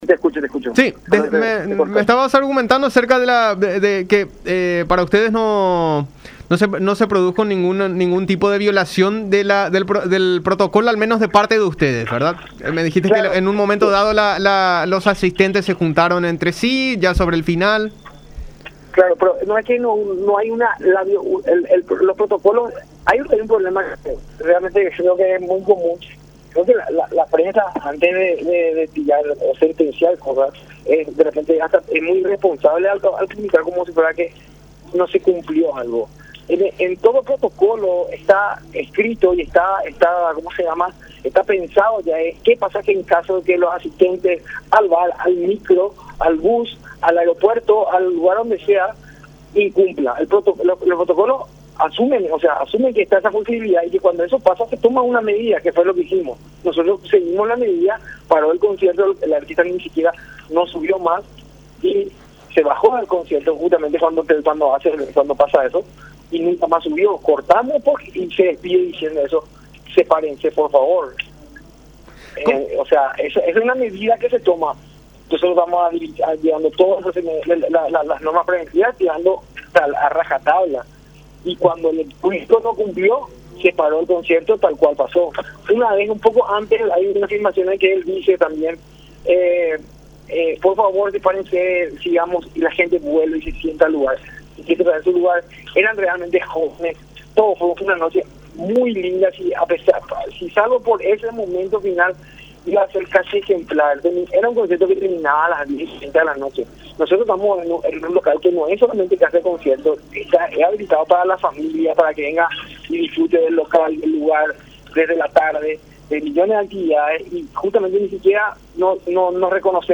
en diálogo con La Unión R800AM